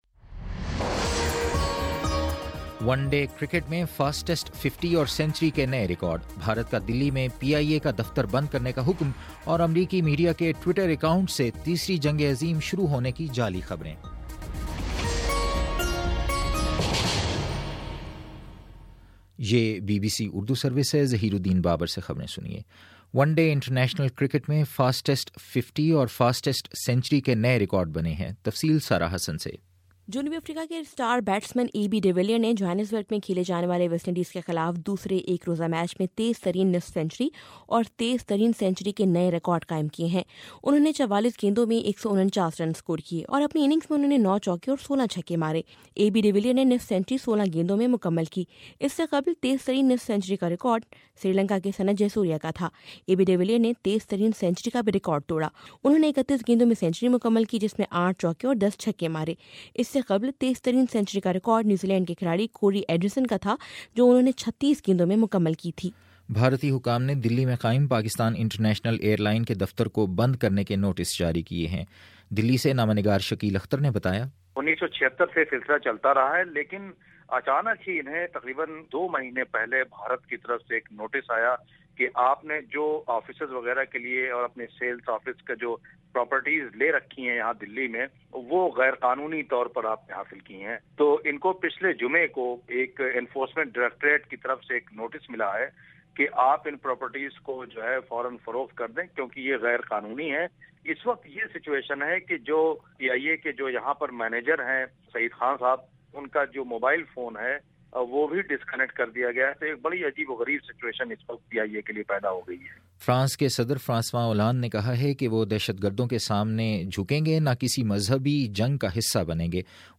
جنوری 18: شام چھ بجے کا نیوز بُلیٹن